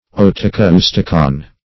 Search Result for " otacousticon" : The Collaborative International Dictionary of English v.0.48: otacoustic \ot`a*cous"tic\, otacousticon \ot`a*cous"ti*con\, n. An instrument to facilitate hearing, as an ear trumpet.